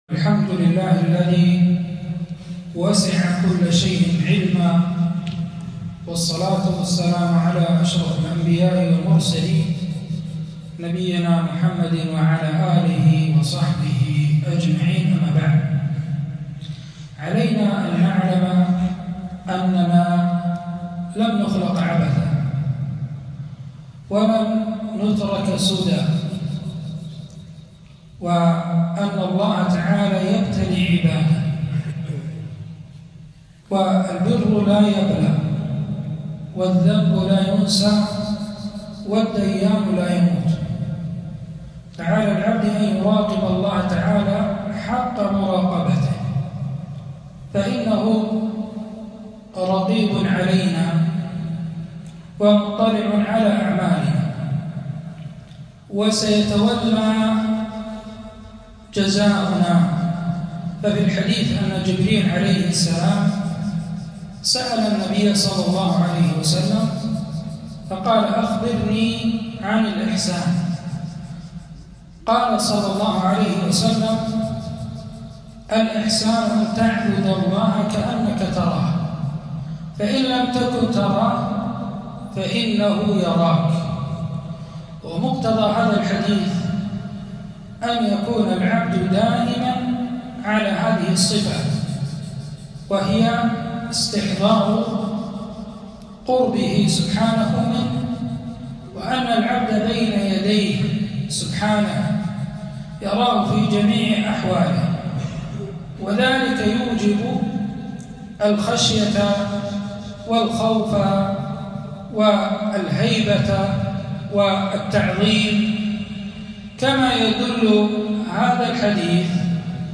محاضرة - مراقبة الله في ضوء الكتاب والسنة